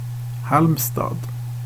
Halmstad (Swedish: [ˈhǎlmsta(d)]